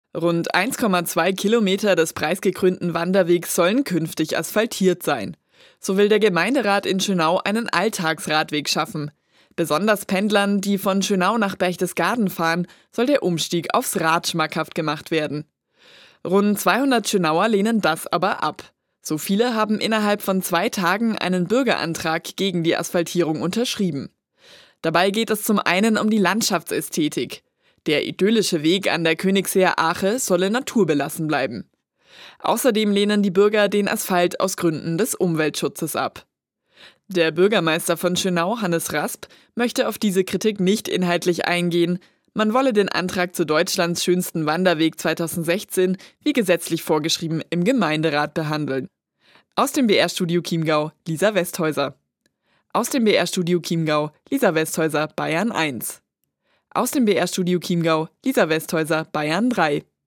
03.02.2023 - Beitrag BR24 -